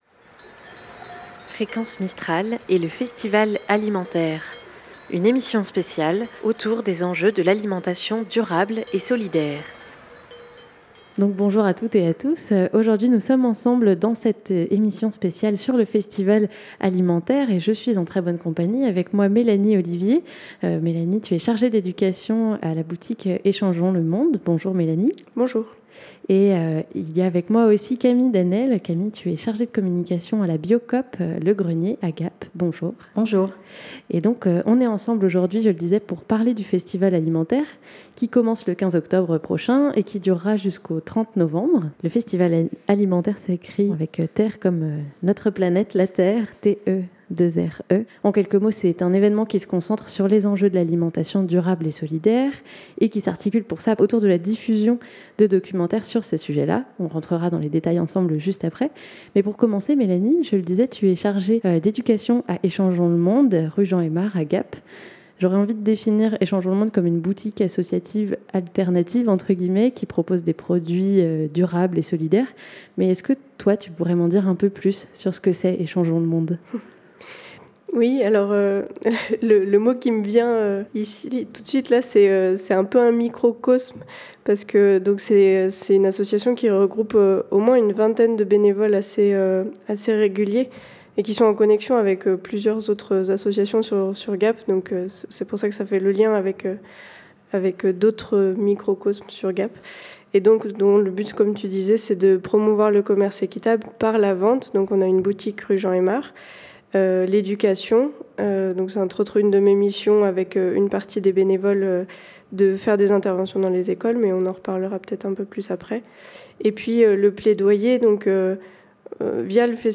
Festival AlimenTerre - Emission Spéciale sur l'alimentation durable et solidaire